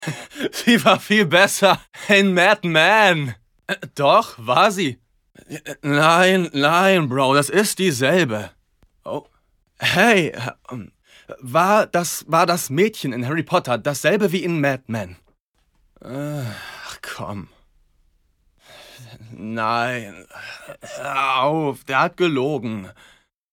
sehr variabel, hell, fein, zart
Jung (18-30)
Lip-Sync (Synchron)